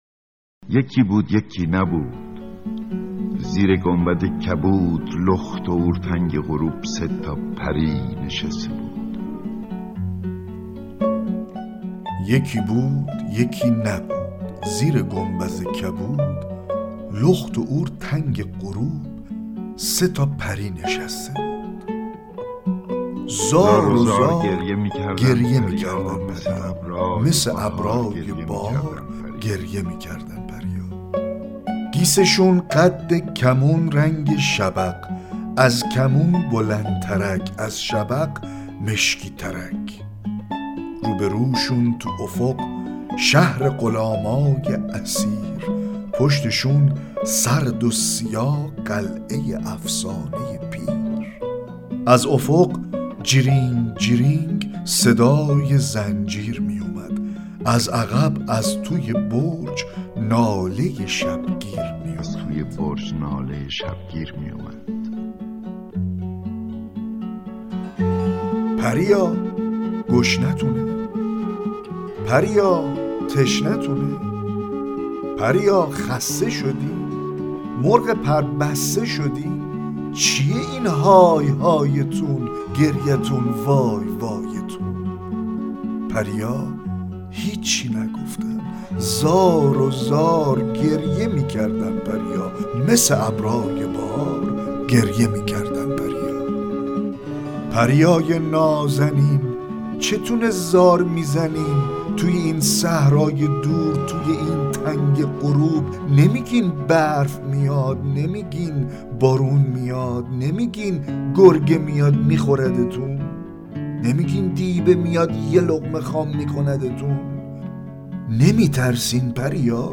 دکلمه شعر پریا با صدای احمد شاملو